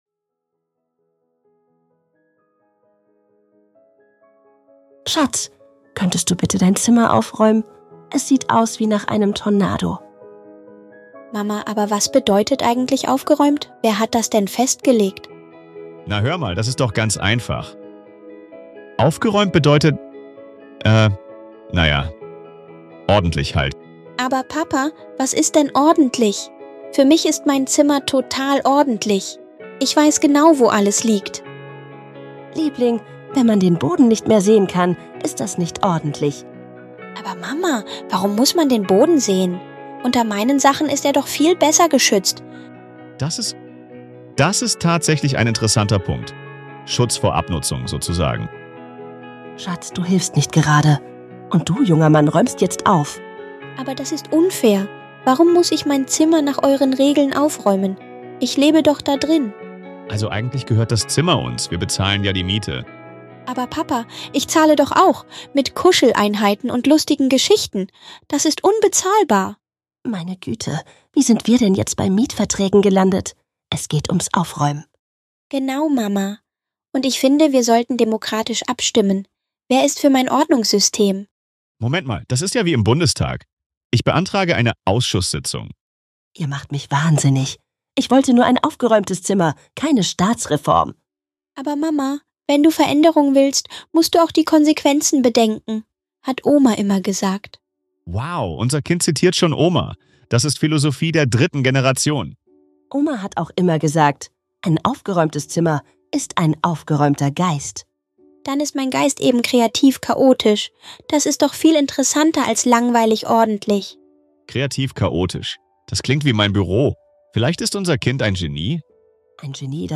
Gespräch zwischen Mama, Papa und ihrem schlauen Kind wird aus einer